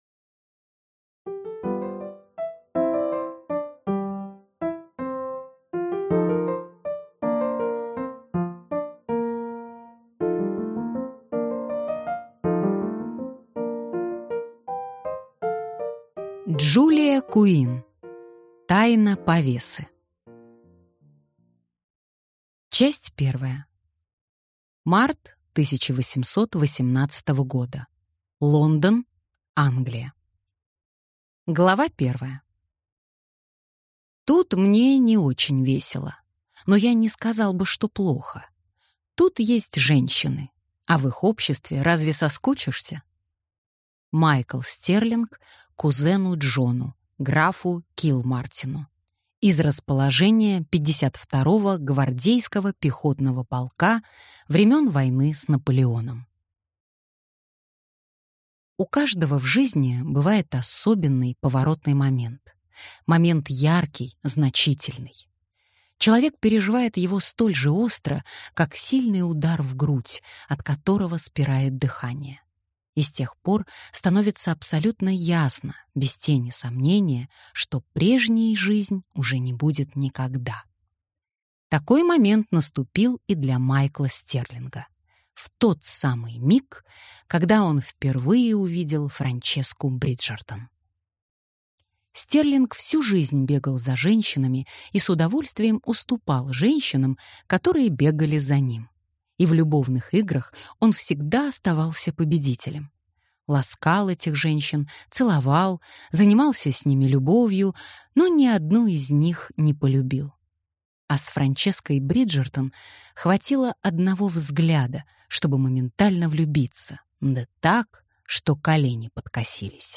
Аудиокнига Тайна повесы | Библиотека аудиокниг